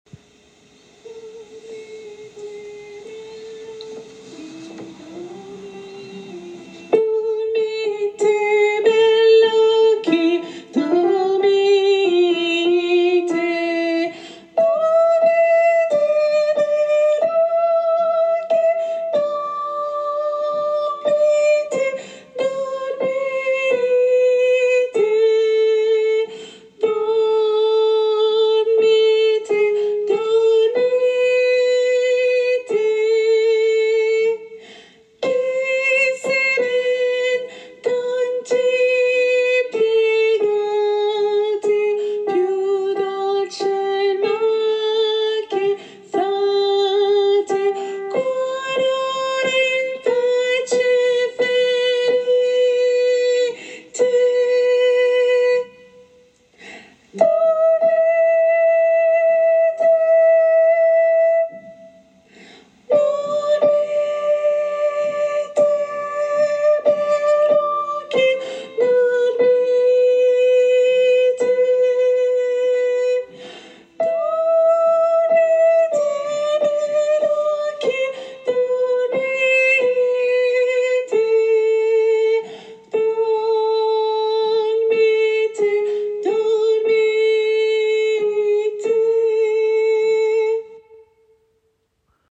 Soprano et autres voix en arrière